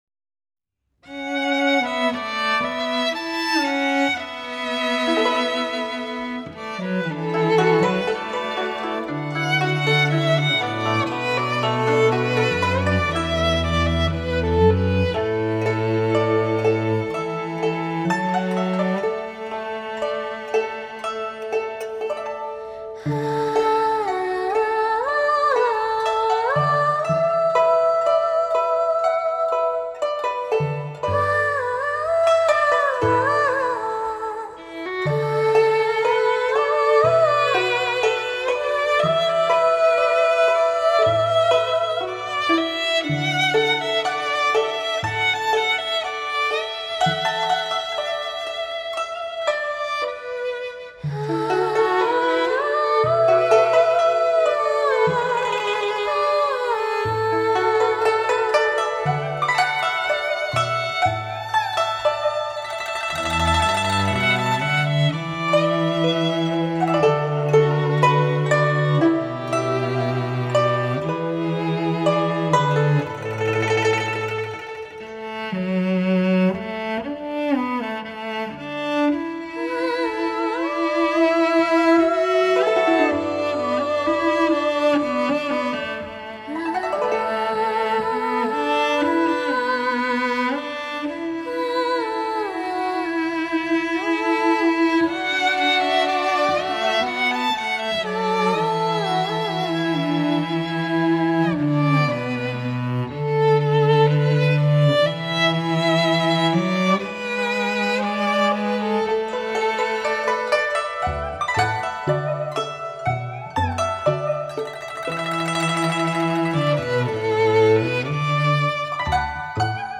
精心编曲 重新演绎 中乐与西乐重奏 音效超卓 令人咋舌
大师力作 激情四溢 经典名曲 恬静柔美 回肠荡气
编曲 配器极高水准 让人耳目一新 母带后期德国精制